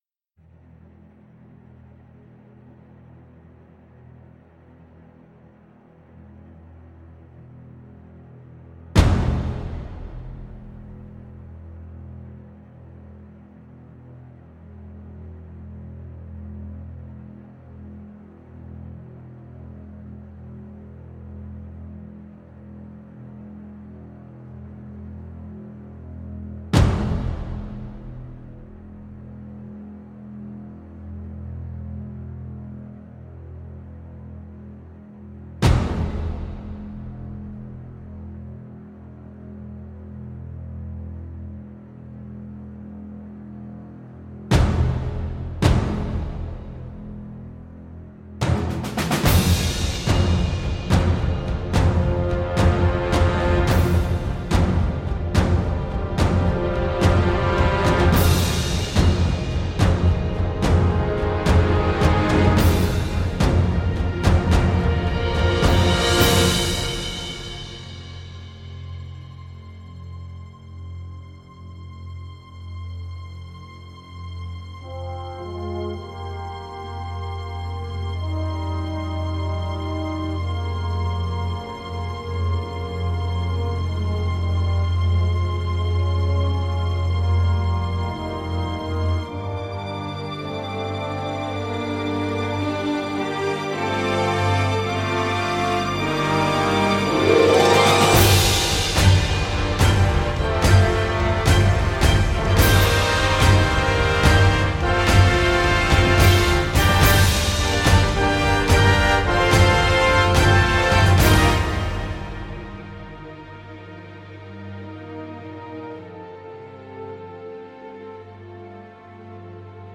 SFX游戏敲鼓咚哒雄伟氛围音效下载
SFX音效